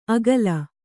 ♪ agalam